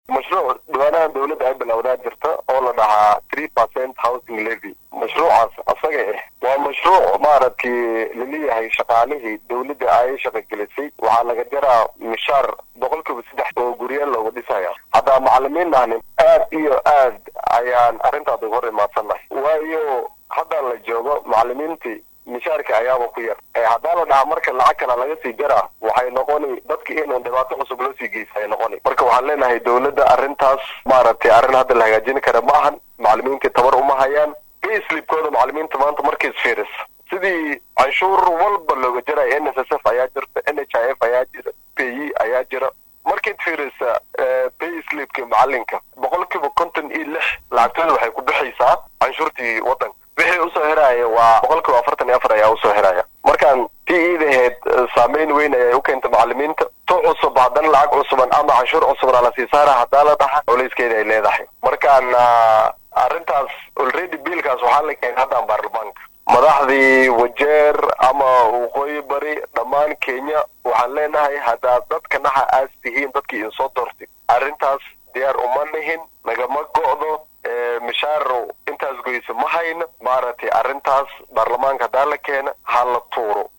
wareysi gaar ah